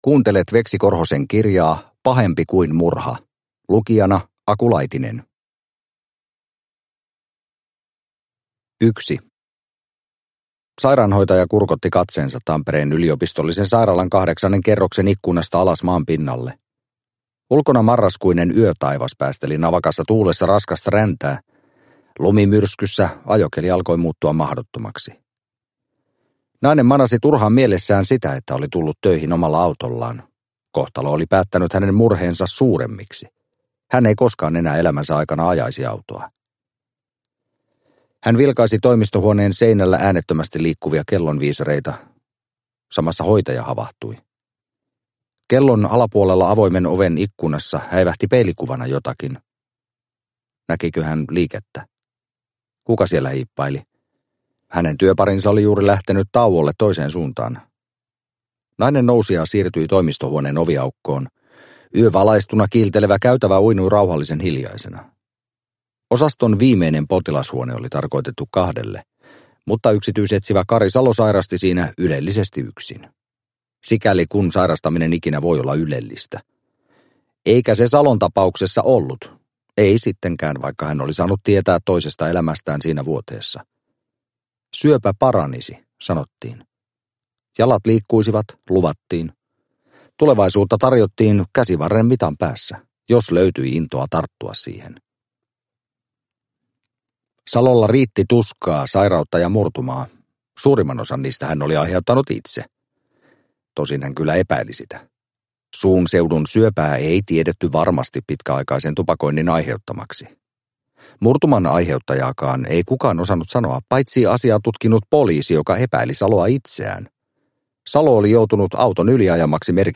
Pahempi kuin murha – Ljudbok – Laddas ner